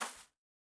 Bare Step Grass Medium C.wav